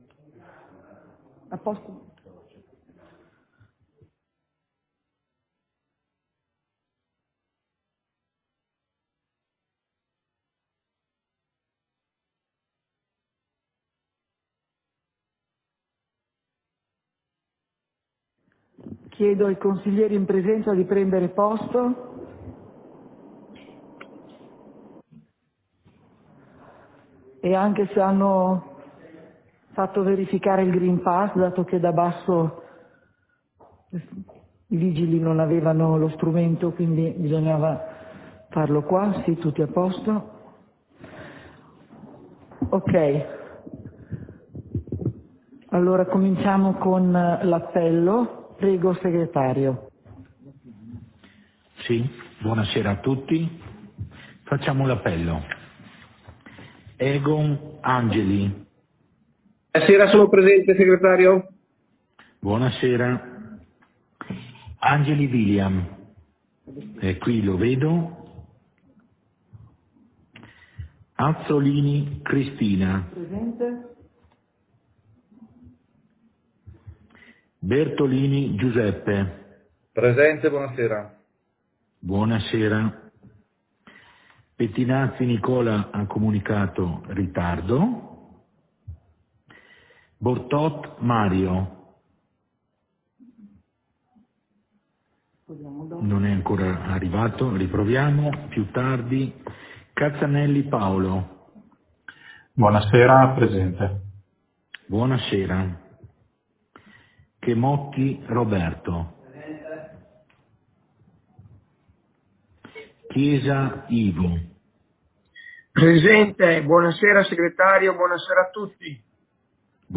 Seduta del consiglio comunale - 26.01.2022